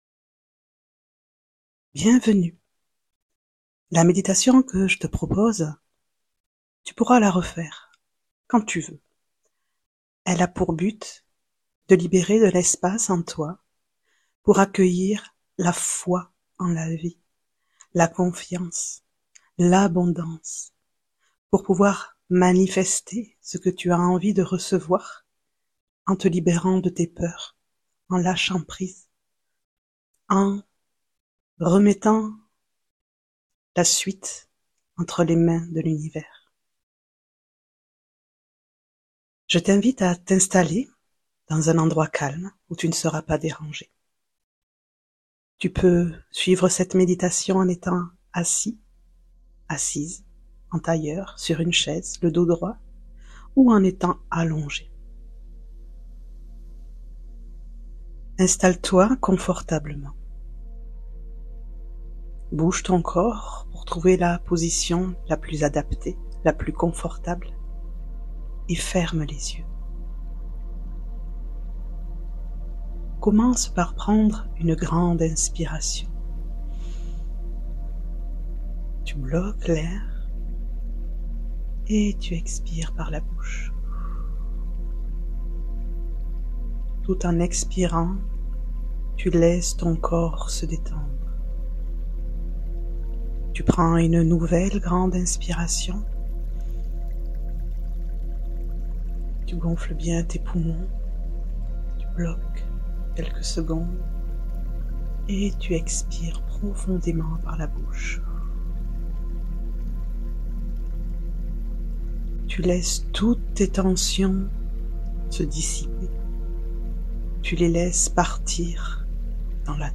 Méditation : s'ouvrir à la foi et à l'abondance